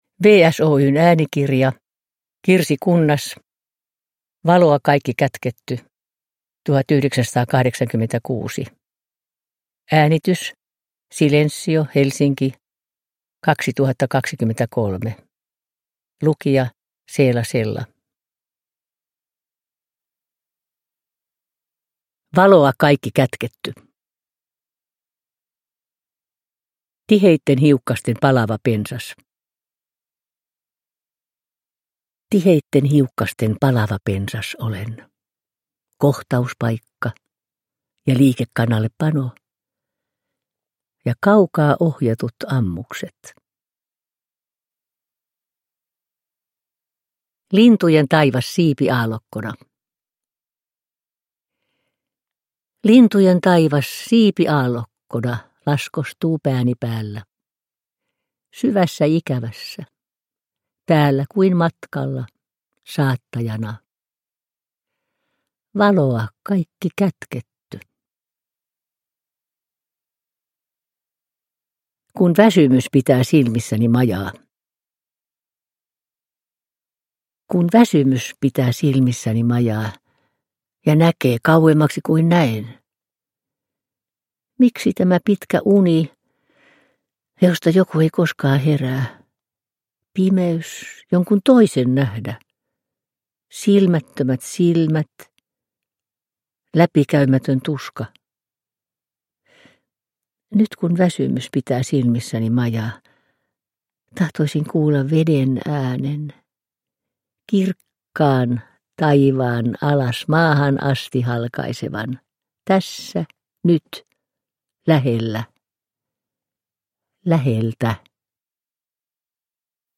Valoa kaikki kätketty – Ljudbok – Laddas ner
Uppläsare: Seela Sella